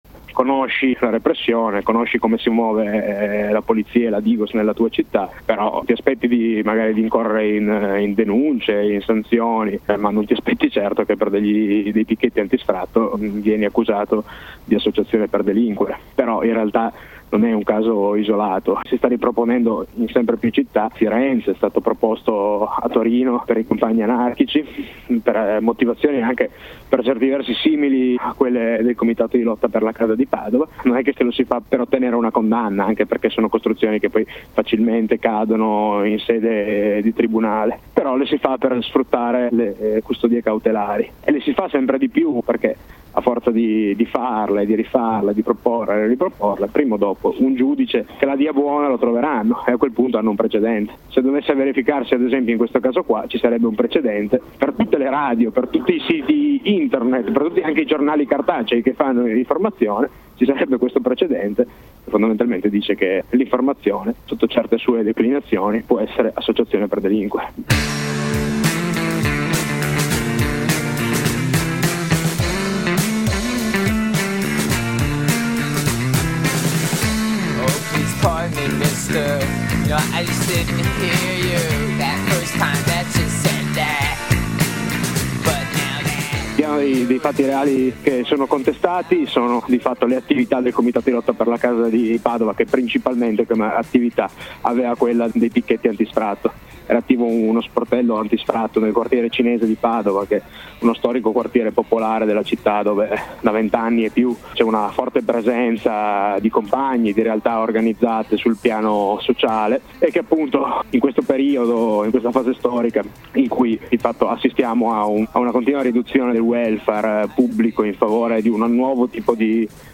Da un compagno di RadiAzione, i contorni dell’operazione repressiva e della lotta contro gli sfratti a Padova.